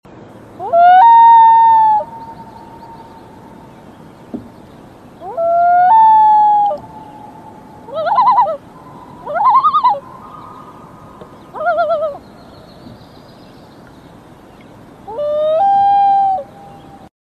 Common Loons duck beautiful voice sound effects free download
Common Loons duck beautiful voice